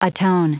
Prononciation du mot atone en anglais (fichier audio)